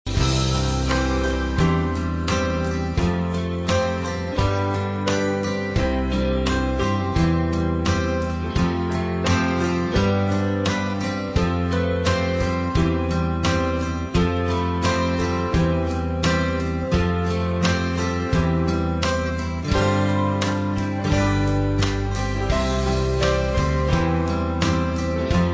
A Winter Holidays Concert Song
Instrumental and vocal tracks